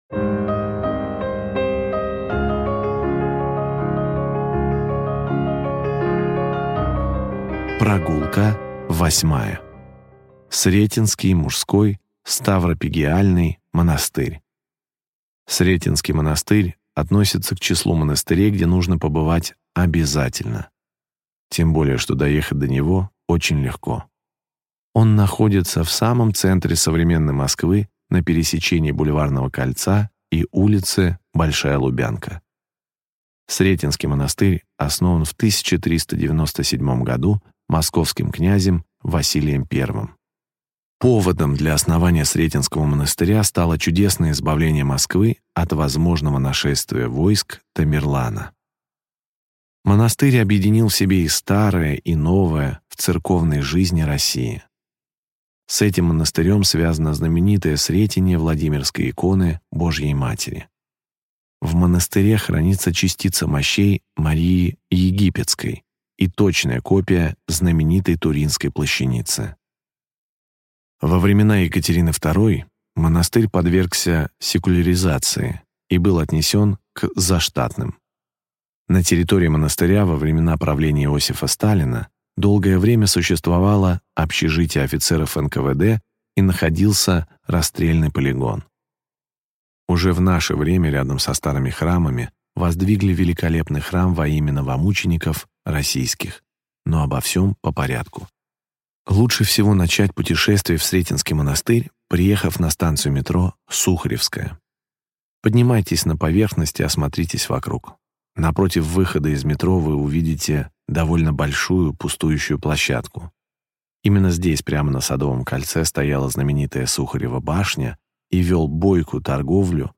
Аудиокнига Монастырский пояс Москвы. Глава 8. Сретенский монастырь | Библиотека аудиокниг